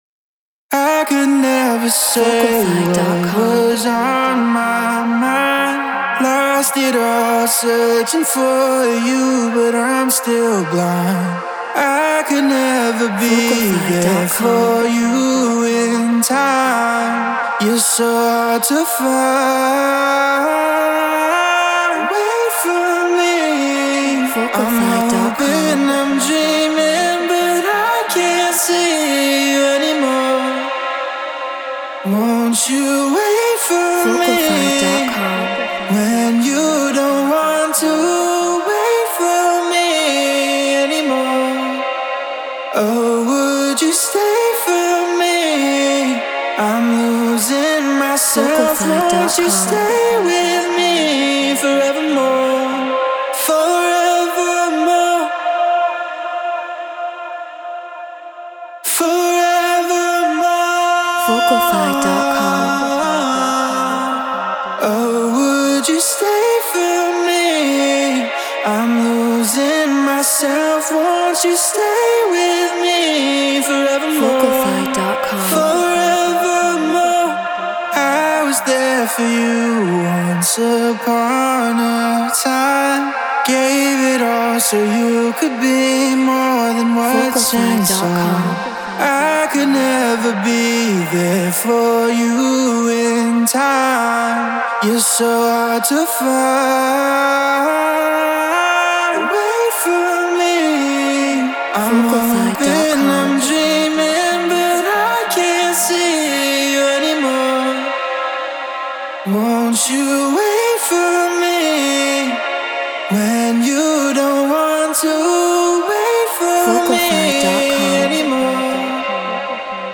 Drum & Bass 174 BPM A#min
RØDE NT1 Focusrite Scarlett Solo FL Studio Treated Room